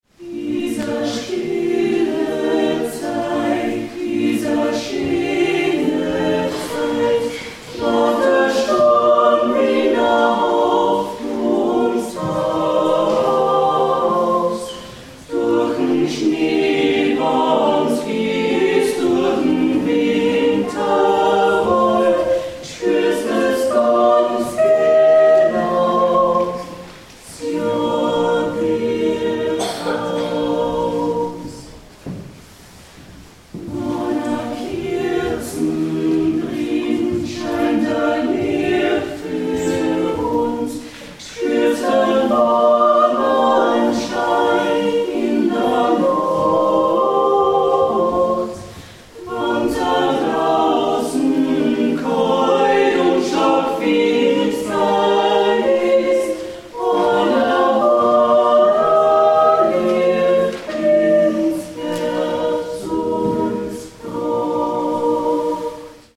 Von Renaissance und Romantik über Beatles-Songs und Volkslieder bis hin zu Pop und zeitgenössischen Stücken ist alles vertreten – kurz gesagt, A-Cappella-Musik aus 400 Jahren.